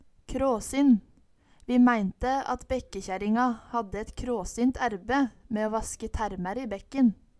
kråsin - Numedalsmål (en-US)